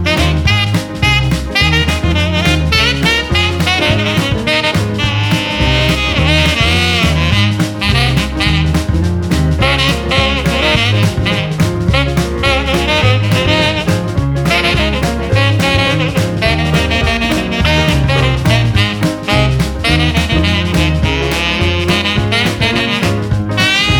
no sax Rock 'n' Roll 2:22 Buy £1.50